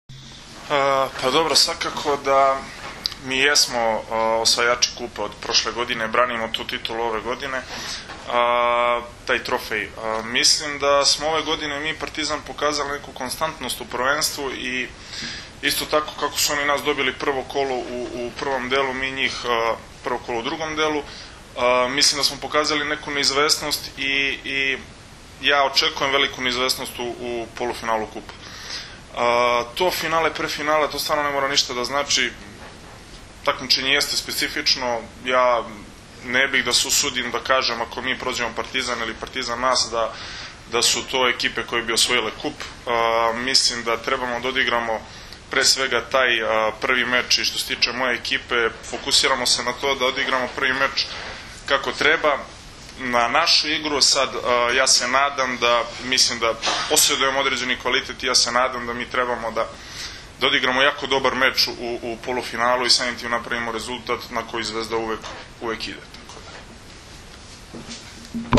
U prostorijama Odbojkaškog saveza Srbije danas je održana konferencija za novinare povodom Finalnog turnira 49. Kupa Srbije u konkurenciji odbojkaša, koji će se u subotu i nedelju odigrati u Hali sportova u Kraljevu.